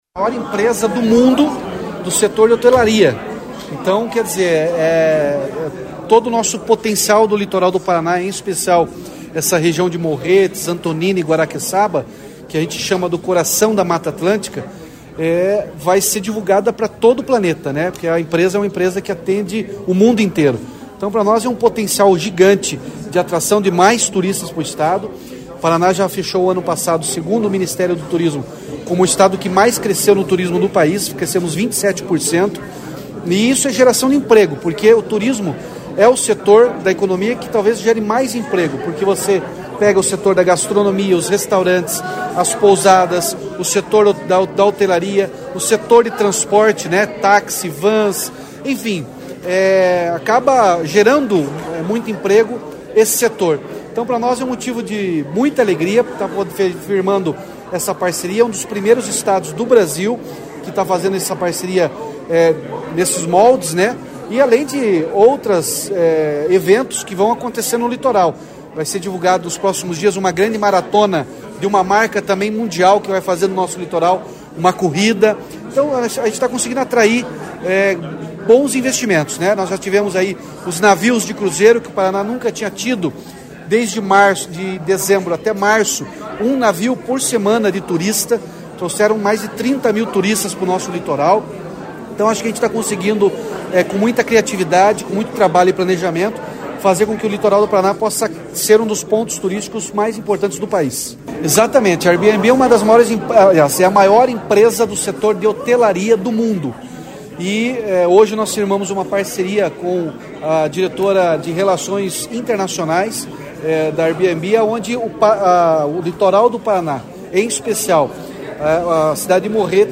Sonora do governador Ratinho Junior sobre o lançamento da rota de turismo sustentável no Litoral em parceria com o Airbnb